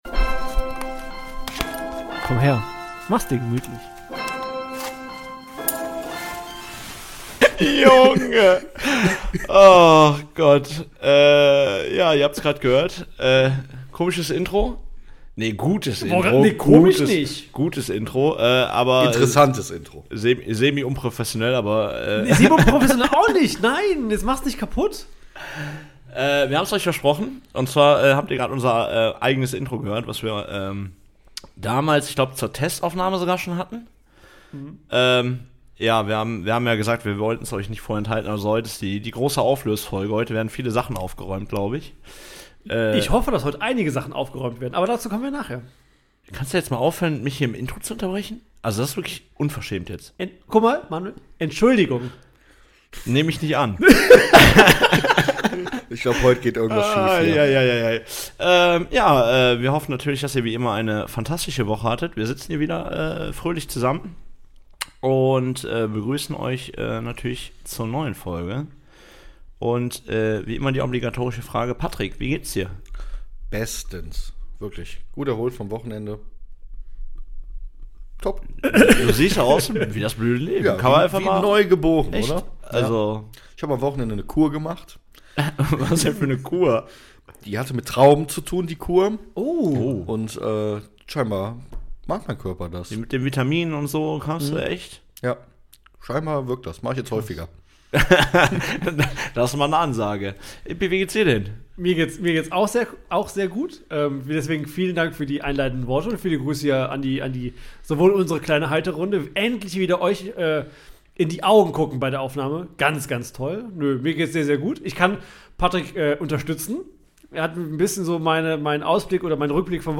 An dieser Stelle entschuldigen wir uns ausdrücklich für die Ohrenschmerzen in dieser Folge.